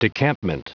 Prononciation du mot decampment en anglais (fichier audio)